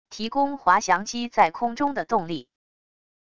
提供滑翔机在空中的动力wav音频